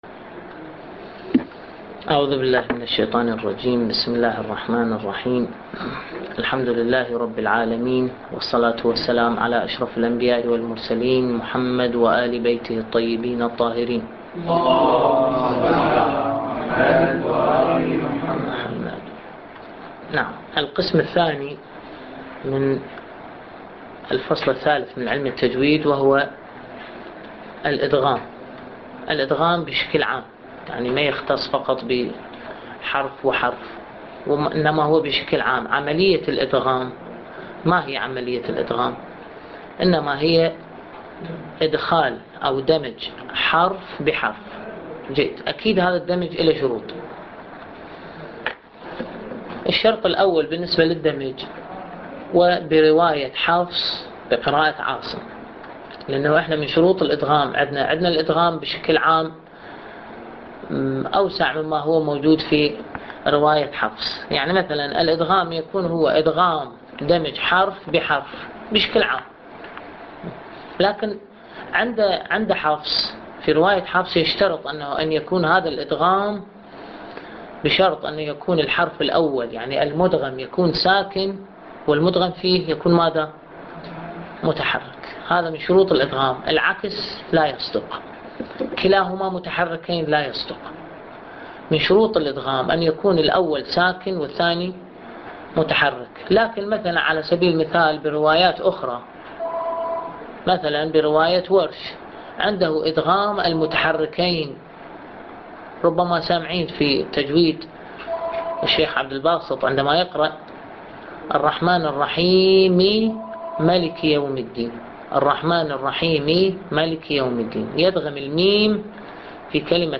الدرس التاسع - لحفظ الملف في مجلد خاص اضغط بالزر الأيمن هنا ثم اختر (حفظ الهدف باسم - Save Target As) واختر المكان المناسب